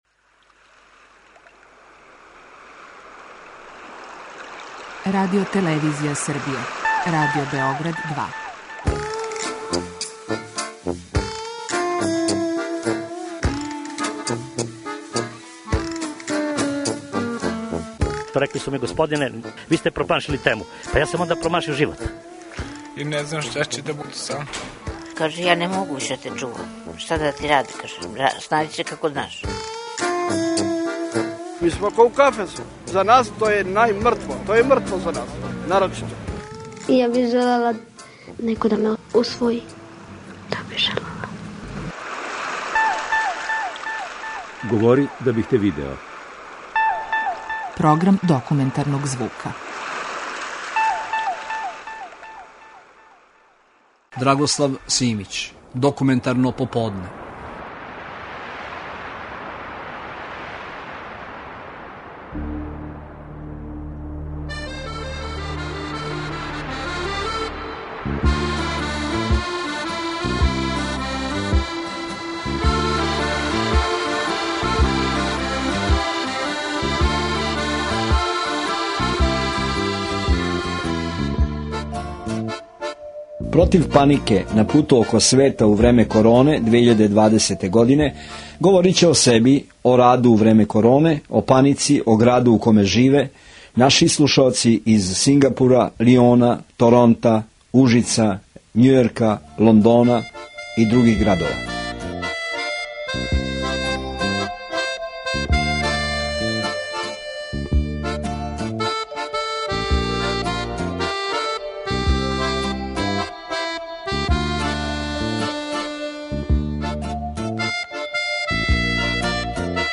Документарни програм
Овај тонски запис снимљен "око планете" док траје корона, начињен је делом те технологије, скајпом.